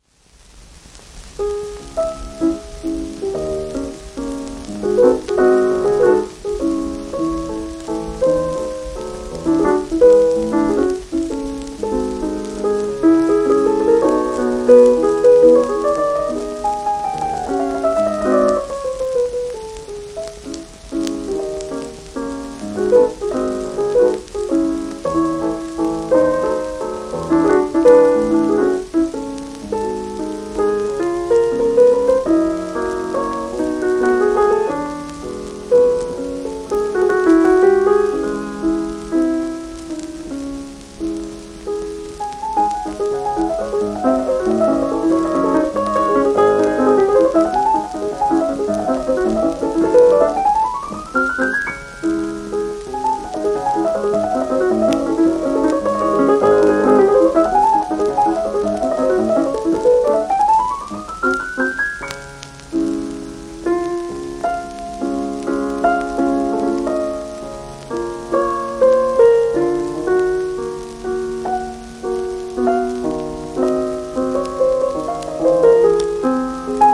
※電気再生向